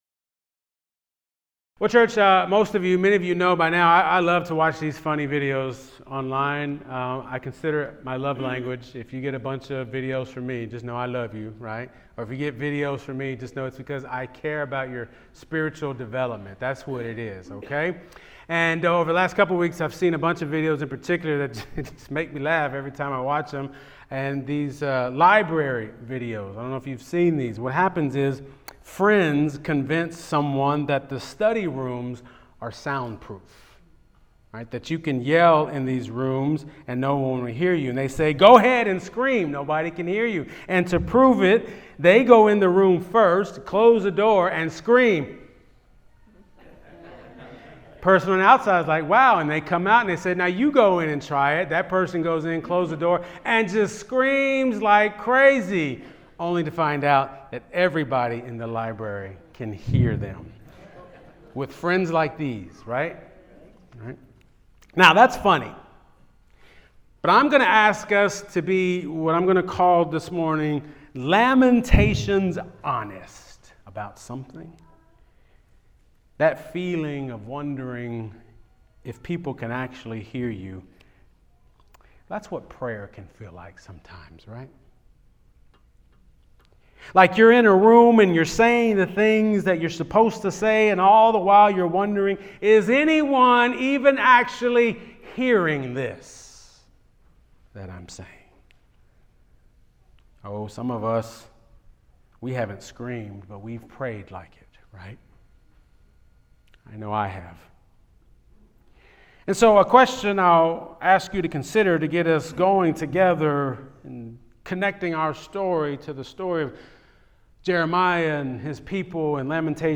Sermons | Kelsey Memorial UMC